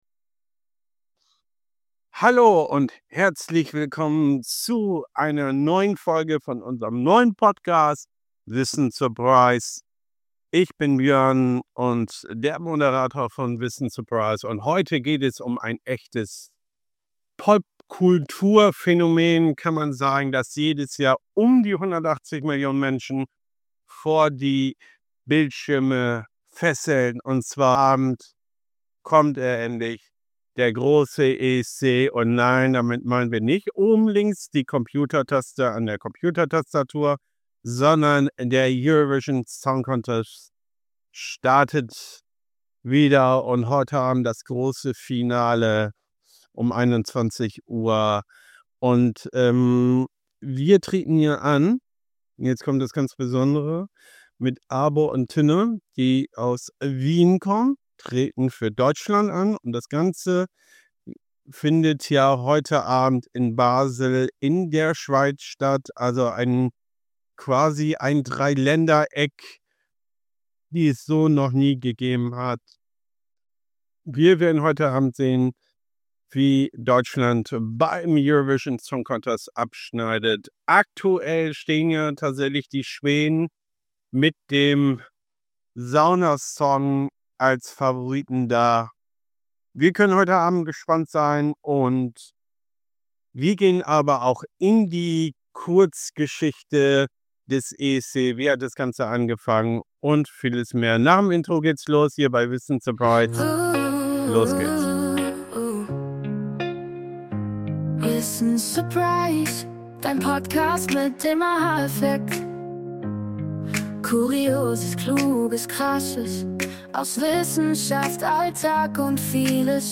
Ich habe ein Interview mit einer KI gemacht zum Thema ESC!
alles-zum-esc-mit-special-guest-ki-clara.mp3